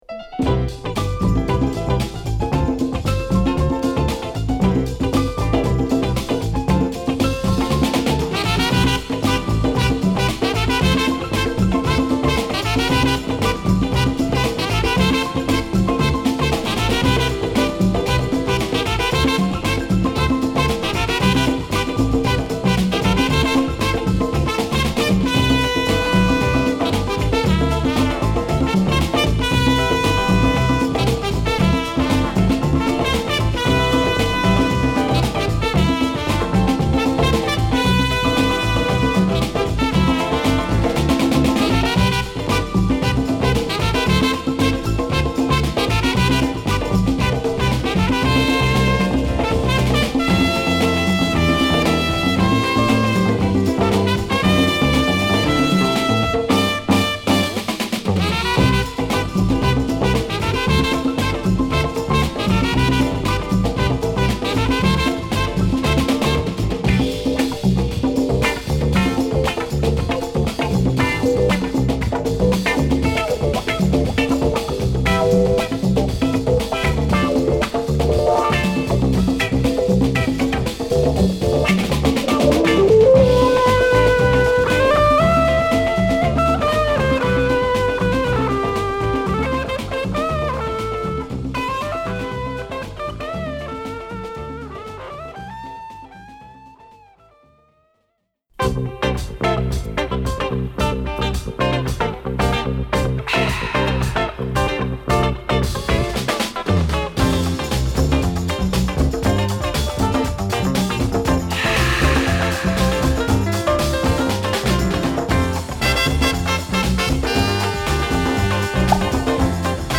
両面共にキラーです！